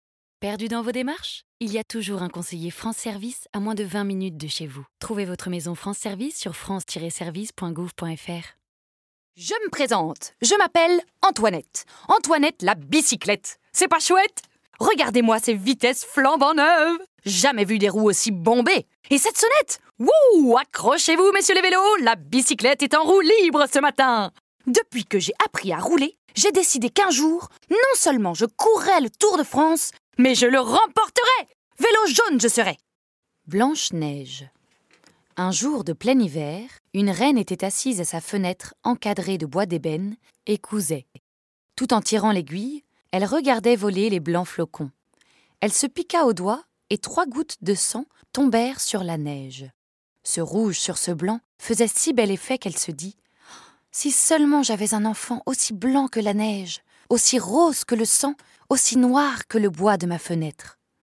Voix off
9 - 35 ans - Mezzo-soprano